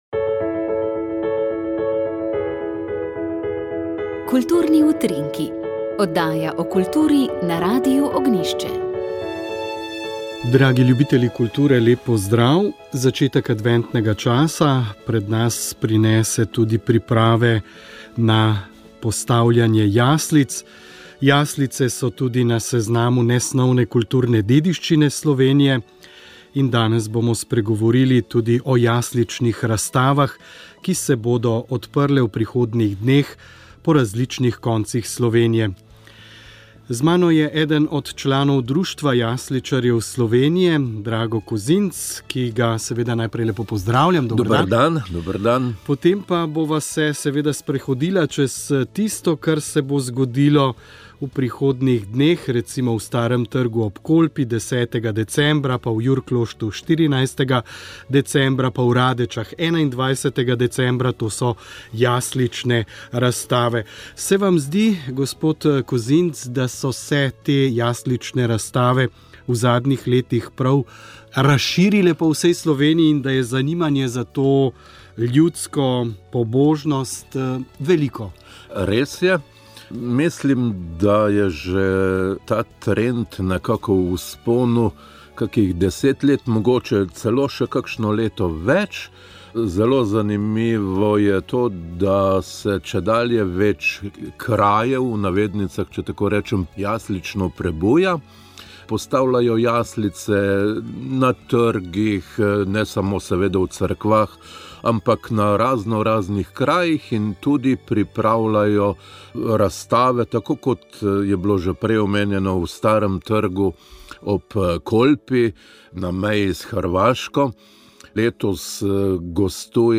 S predstavniki lokalne skupnosti, civilne iniciative in policije smo spregovorili o reševanju romske problematike na jugovzhodu države. Dotaknili smo se trenutnih varnostnih razmer, ukrepov v sklopu Šutarjevega zakona, dodatno predlaganih rešitev na področju socialne in delovne aktivacije ter pričakovanj različnih akterjev.